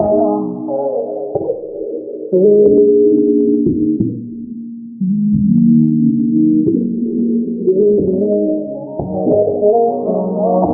LOOP - HER SAY.wav